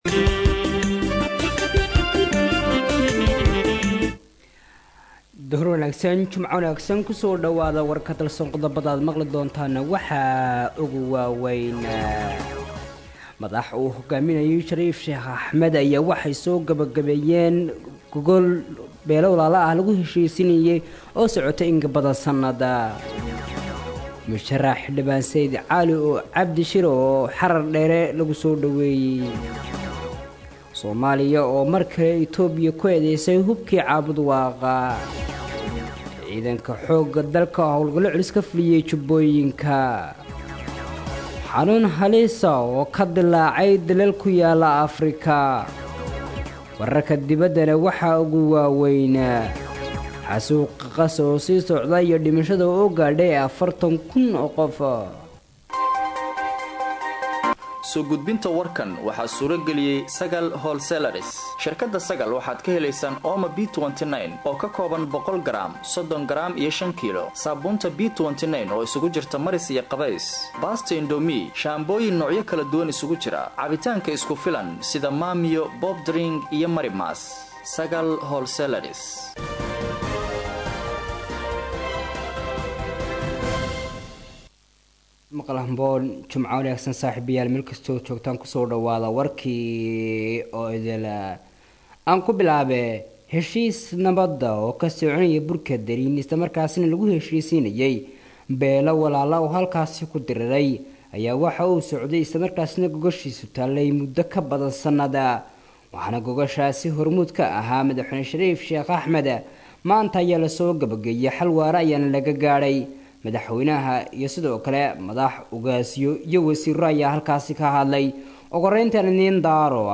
Dhageyso:-Warka Duhurnimo Ee Radio Dalsan 16/08/2024